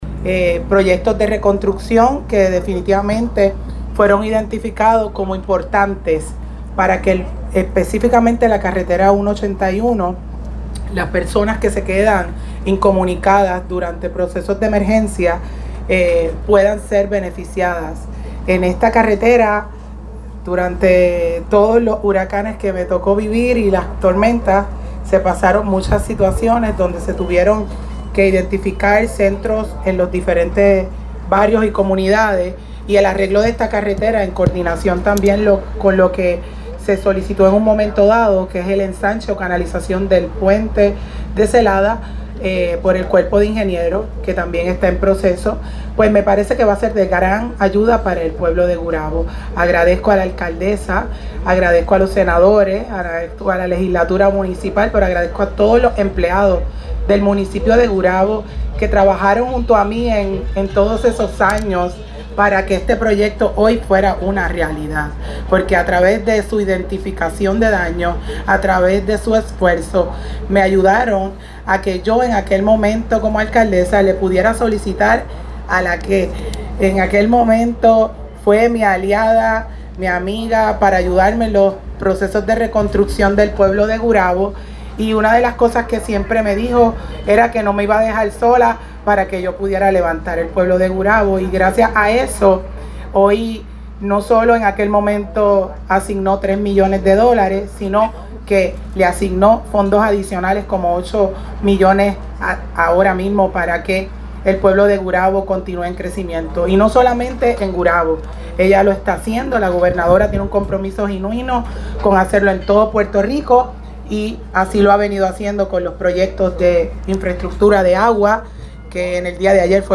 La mandataria hizo el anuncio junto a la secretaria de Estado y entonces alcaldesa de Gurabo cuando primero se solicitaron los fondos para la reconstrucción de la vía Rosachely Rivera, quien dijo estar complacida con el logro obtenido a beneficio de los residentes del pueblo que lideró.